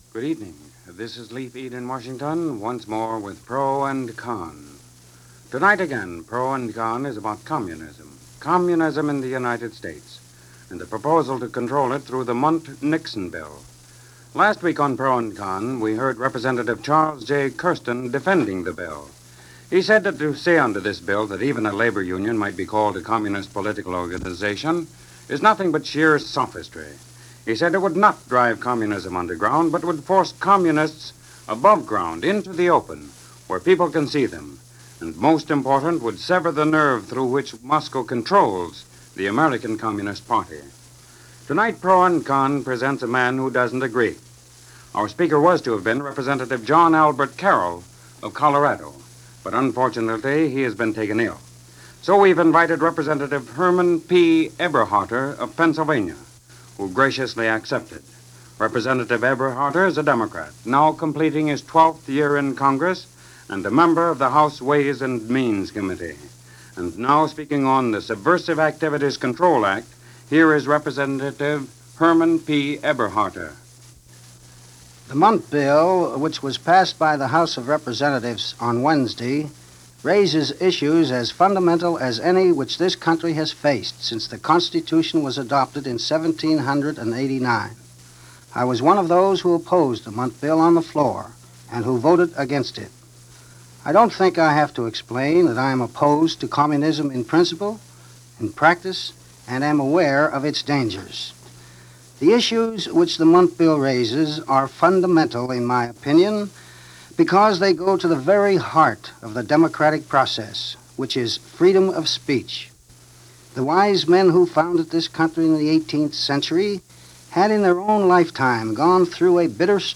Debate on the Mundt-Nixon Bill